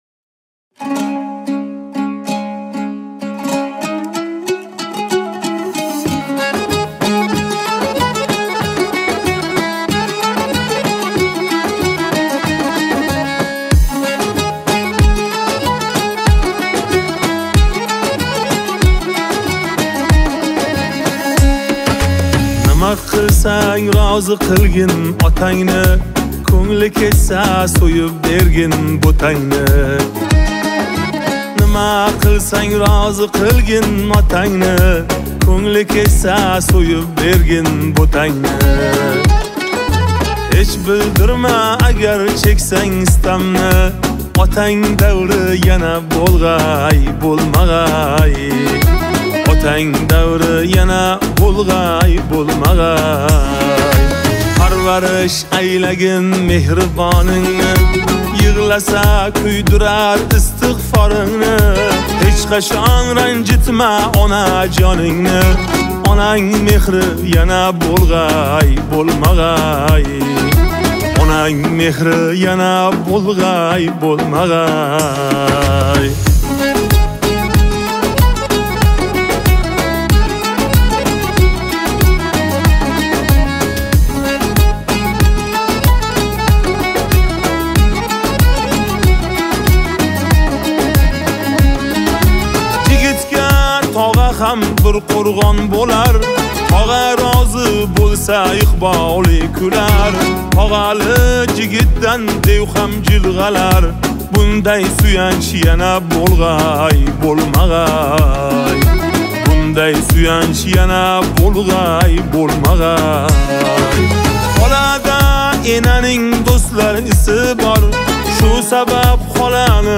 Категория: Узбекские